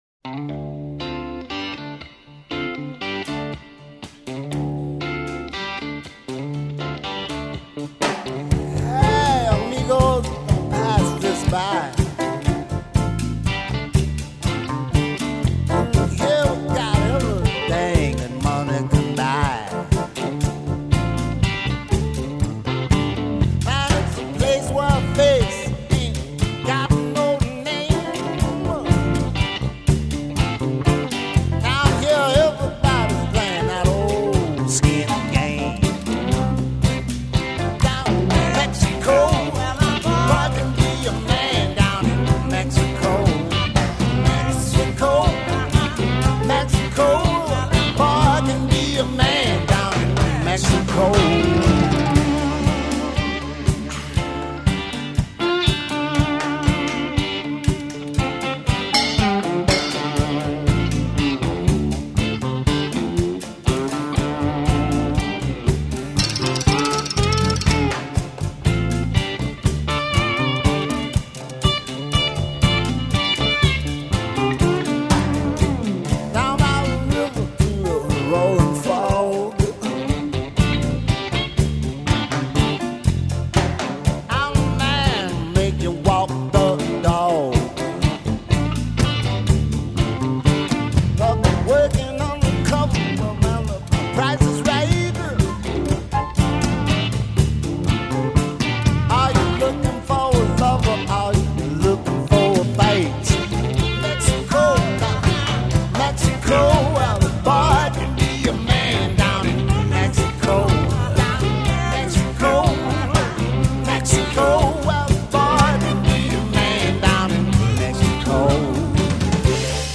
Guitars
Piano
Bass
Drums
Organ
Percussion